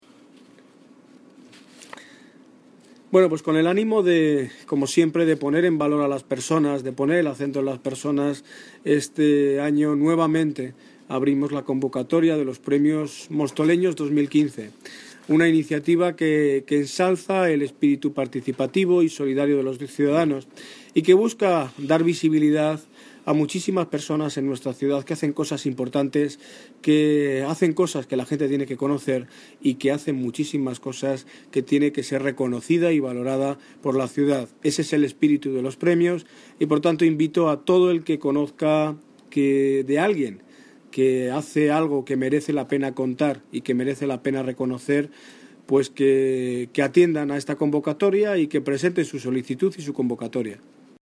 Audio - Daniel Ortiz (Alcalde de Móstoles) Sobre Premios Mostoleños